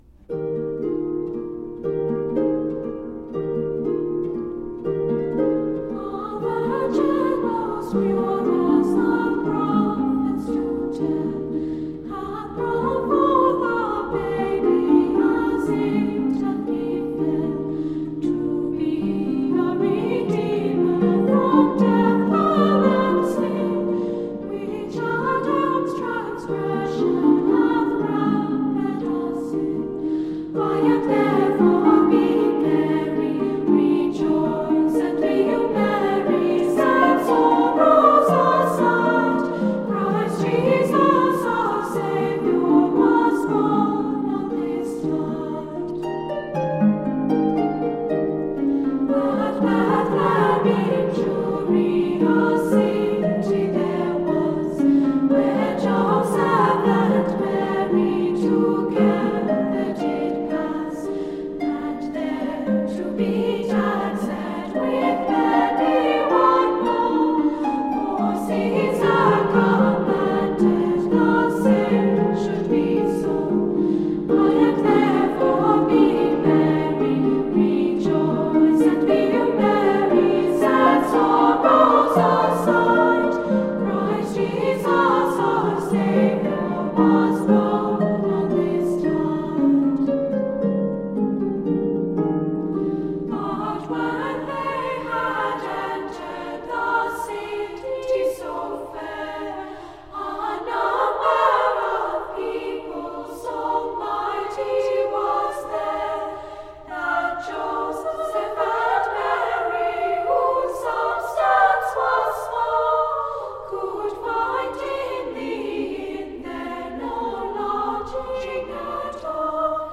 carols
absolutely angelic sounding
children's choir